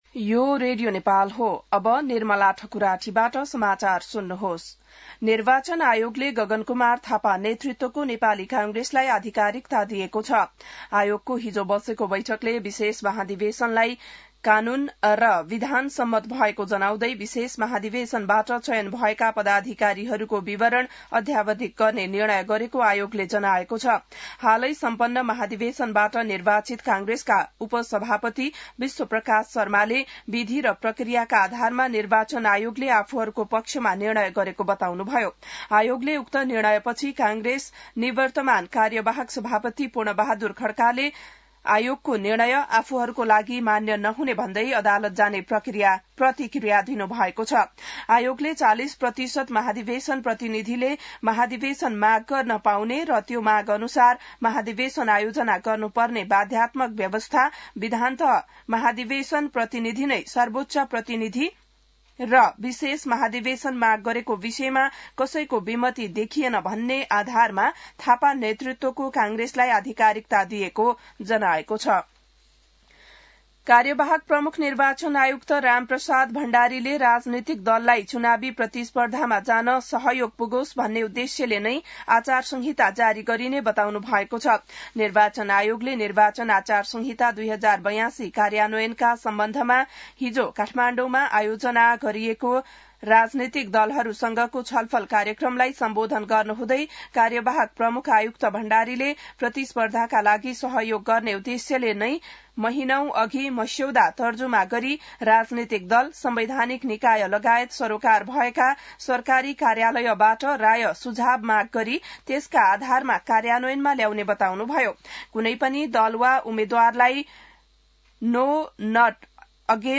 बिहान ६ बजेको नेपाली समाचार : ३ माघ , २०८२